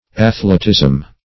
Search Result for " athletism" : The Collaborative International Dictionary of English v.0.48: Athletism \Ath"le*tism\, n. The state or practice of an athlete; the characteristics of an athlete.